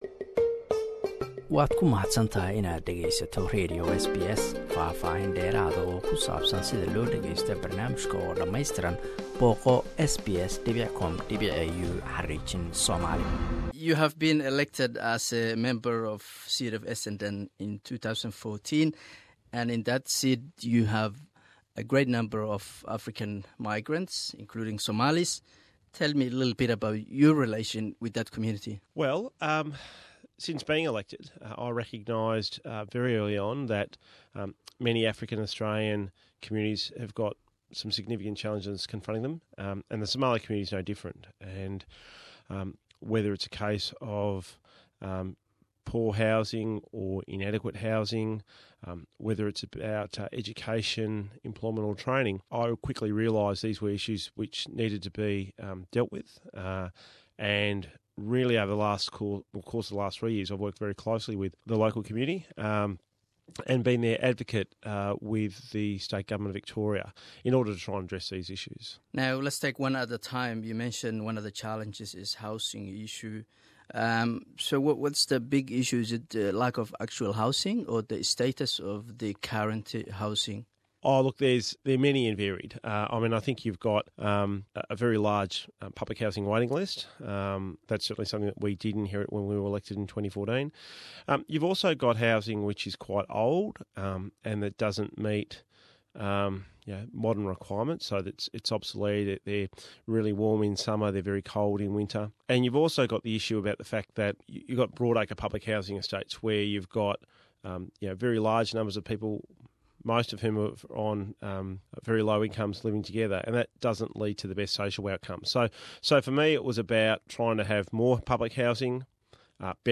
Interview: Danny Pearson, state member for Essendon.